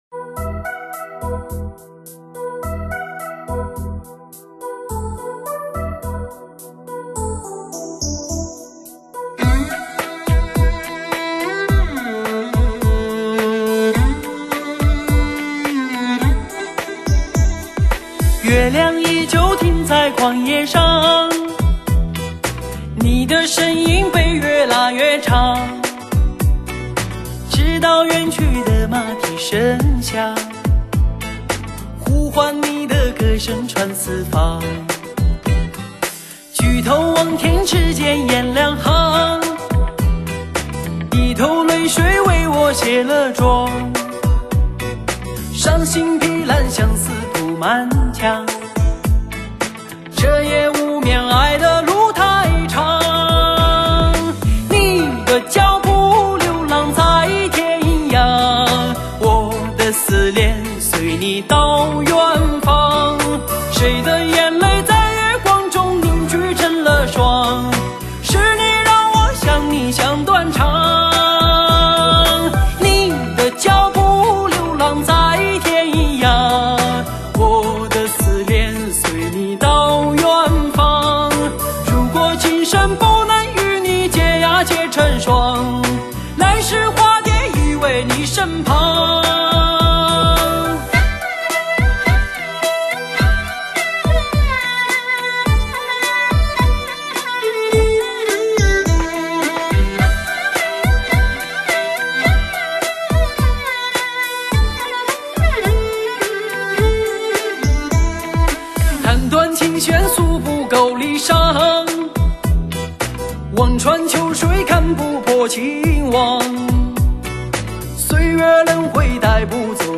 用最纯净的嗓音，描绘情归何处……